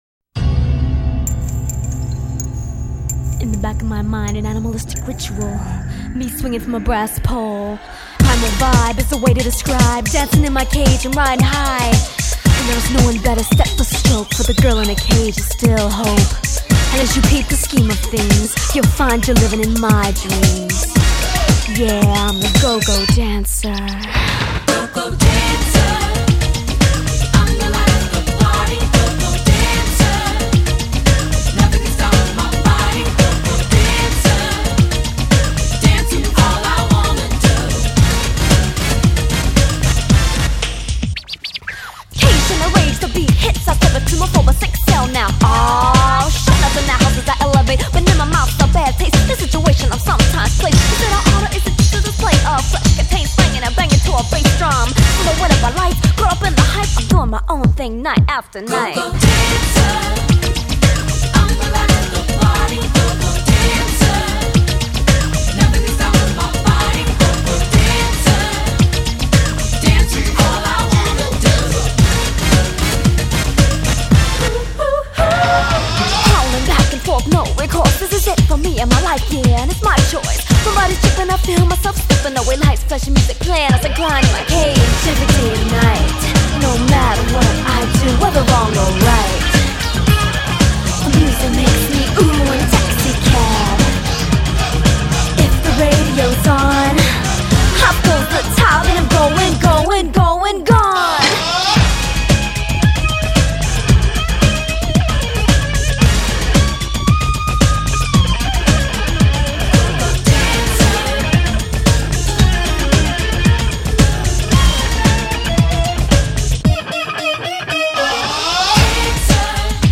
pop R&B